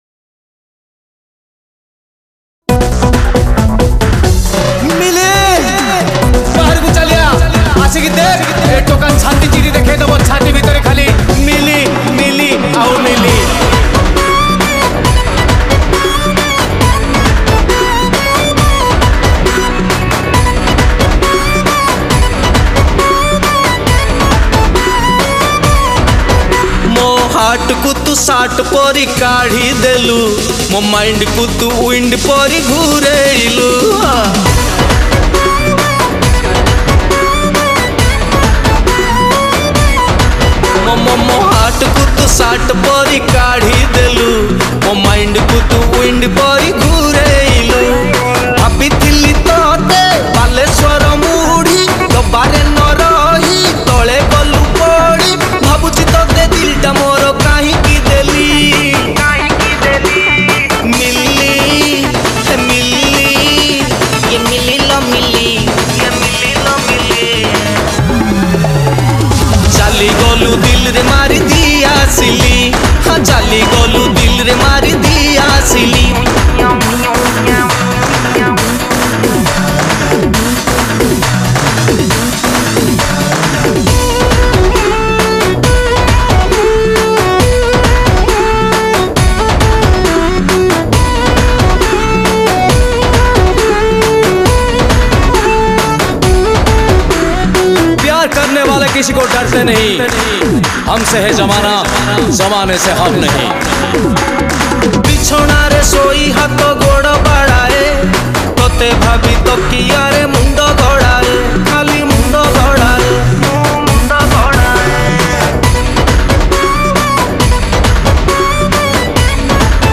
Odia Funny Sad Song